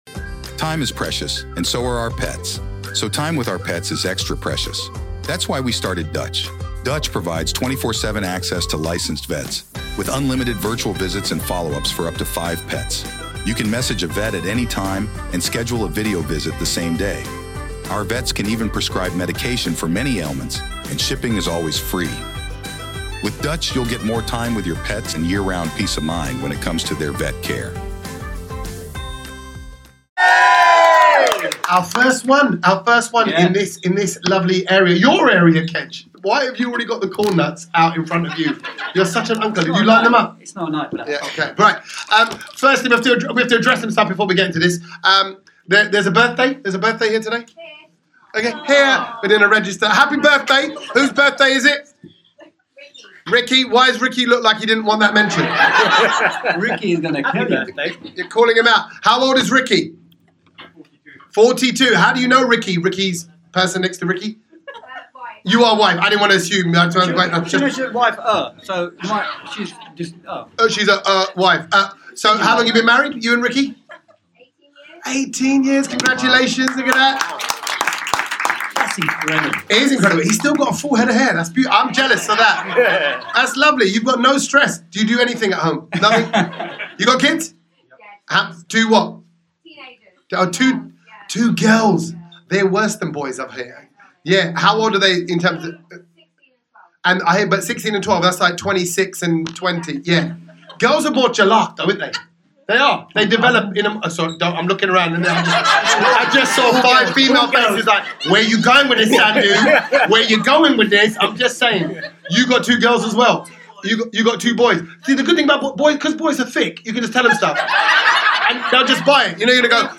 Recorded in front of a live audience in Harrow…